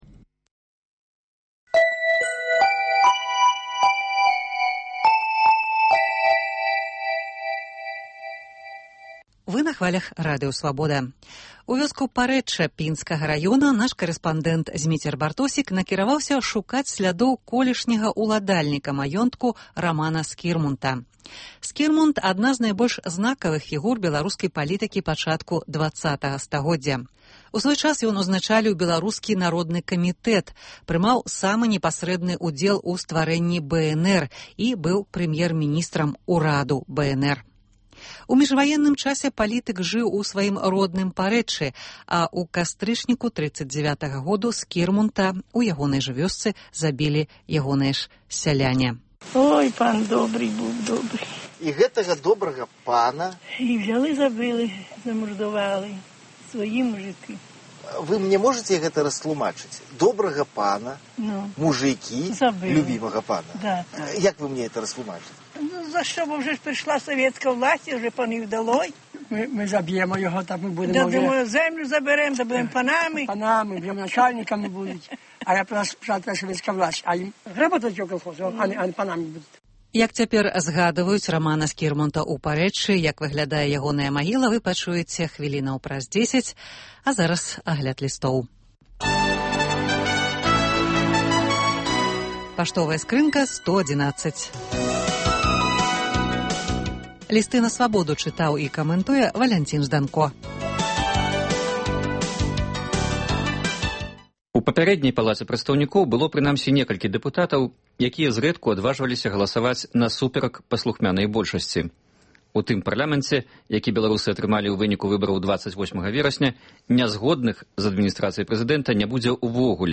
Госьць у студыі адказвае на лісты, званкі, СМСпаведамленьні